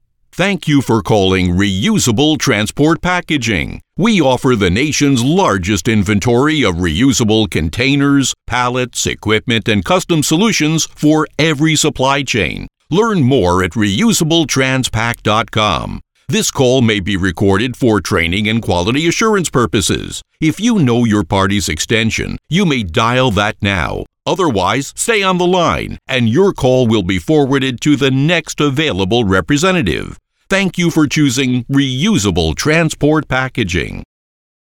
Comercial, Profundo, Natural, Llamativo, Amable
Telefonía